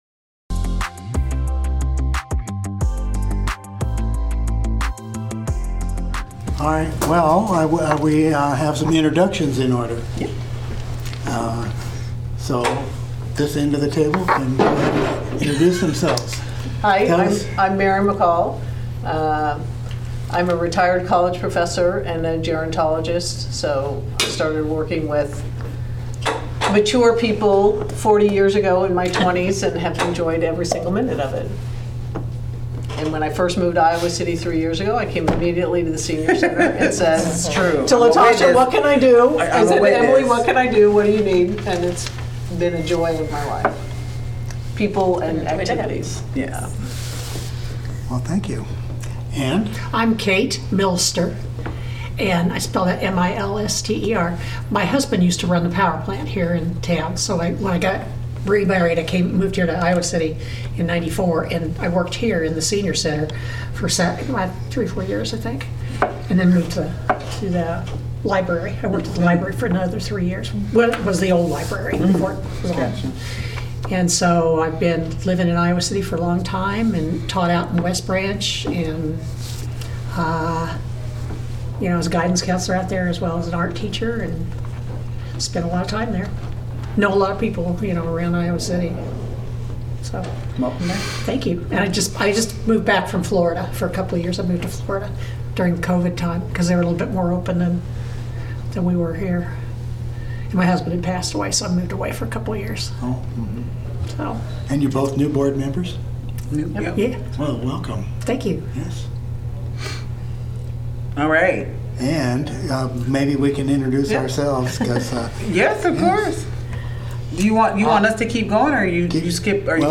Regular monthly meeting of the Senior Center Commission.